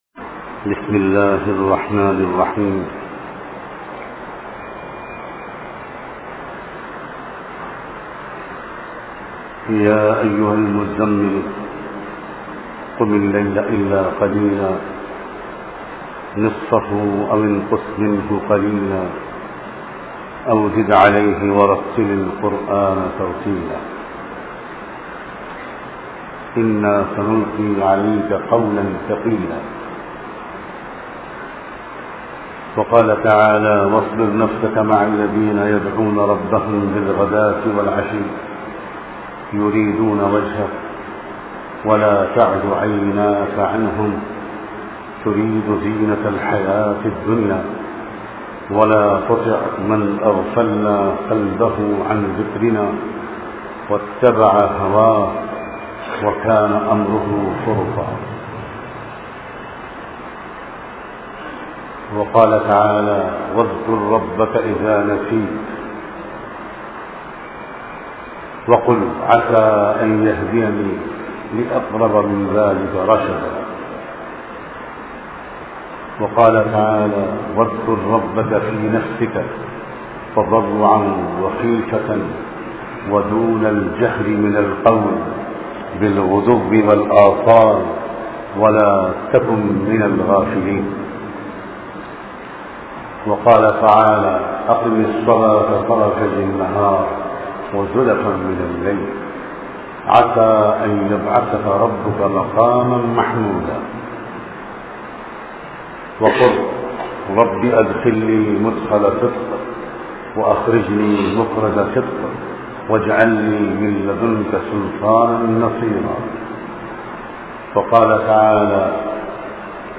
Apni Mukammal Islah Ke Liye Mukammal Hawalgi Zaroori Hai bayan MP3